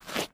MISC Concrete, Foot Scrape 07.wav